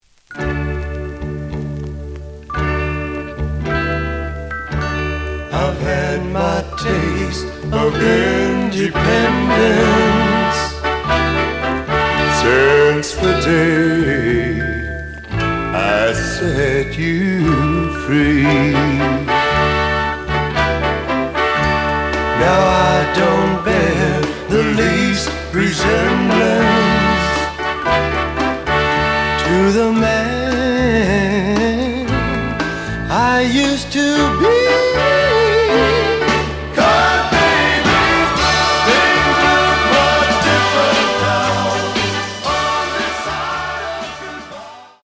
The audio sample is recorded from the actual item.
●Genre: Rock / Pop
Some click noise on first half of A side due to scratches.)